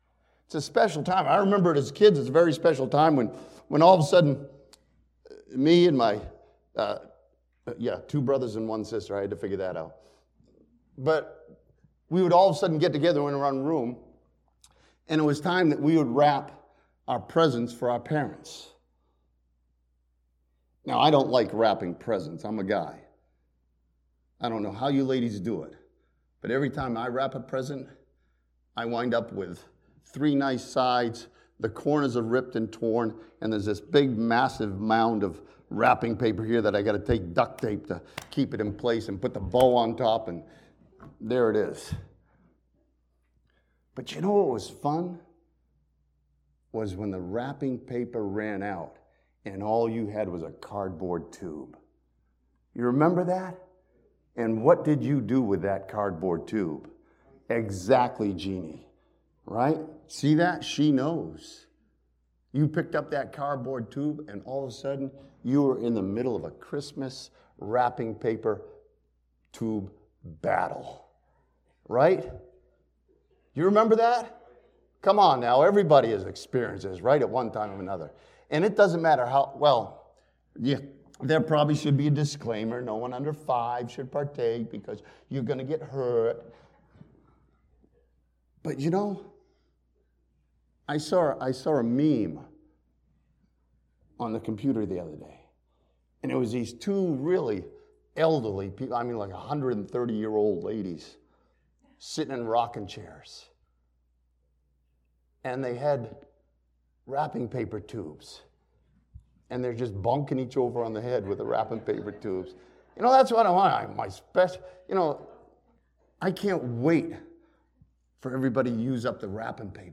This sermon from Luke chapter 2 sees Jesus Christ as the One who was sent to earth to be our Deliverer.